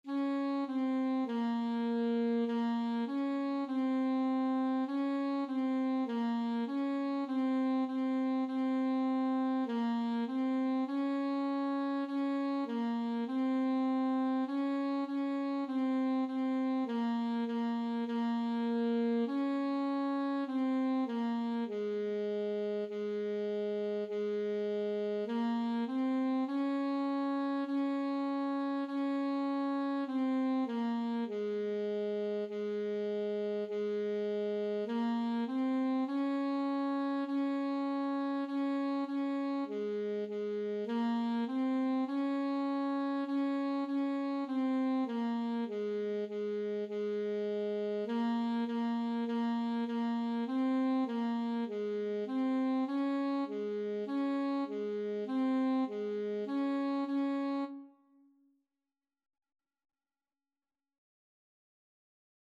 Free Sheet music for Alto Saxophone
4/4 (View more 4/4 Music)
Ab4-Db5
Saxophone  (View more Beginners Saxophone Music)
Classical (View more Classical Saxophone Music)